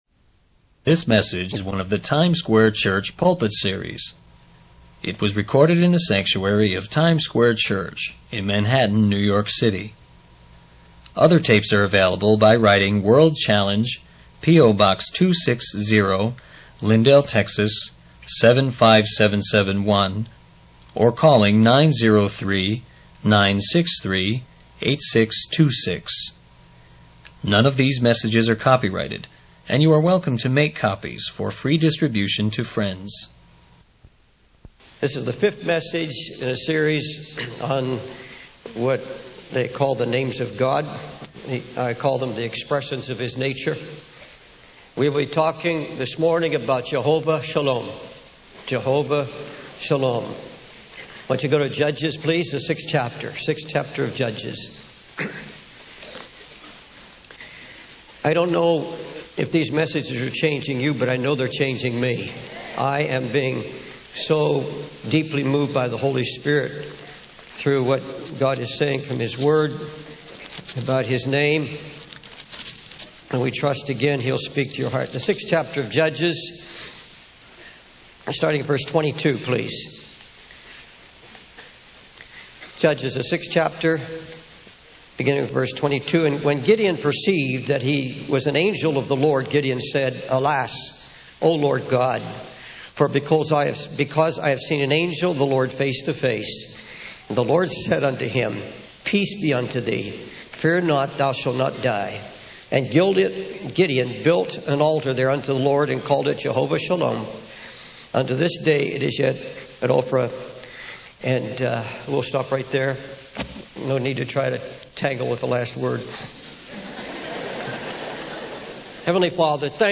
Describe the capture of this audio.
In this sermon, the speaker focuses on the book of Judges and the cycle of sin and repentance that the Israelites went through.